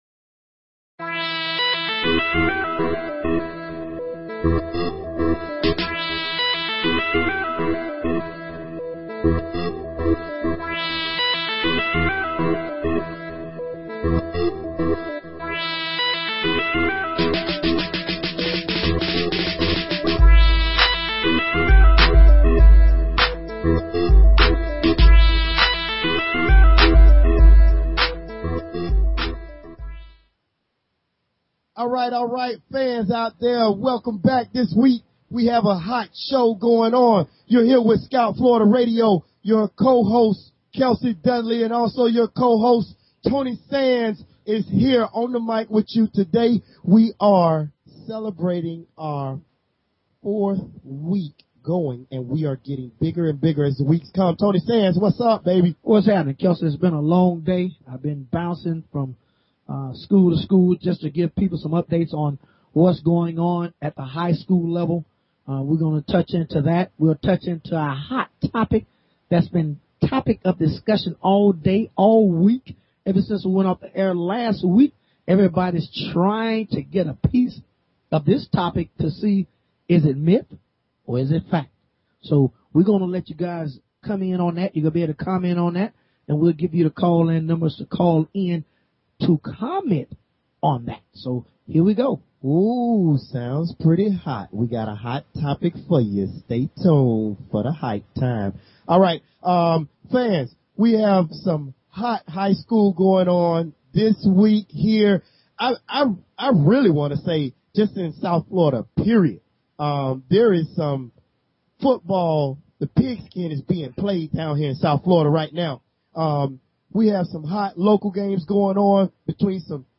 Talk Show Episode, Audio Podcast, Scout_Florida and Courtesy of BBS Radio on , show guests , about , categorized as